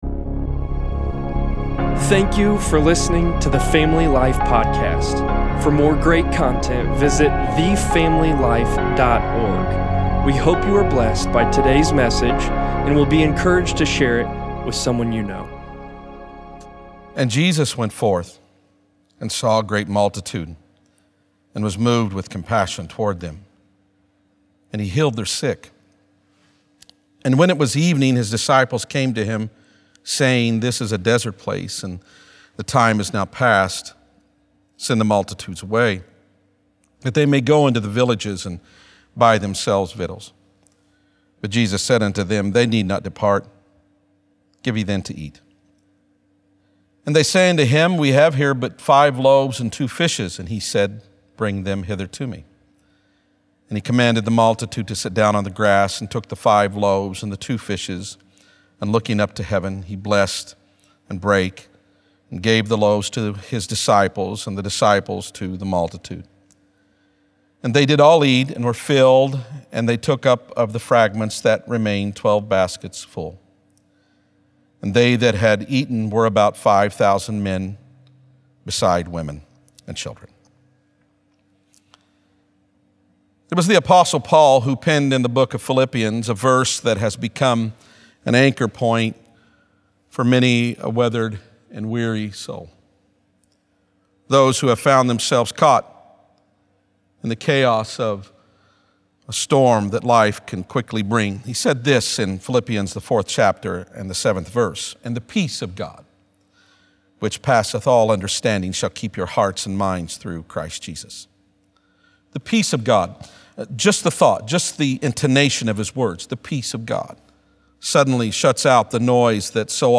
5.3.20_sermon_p.mp3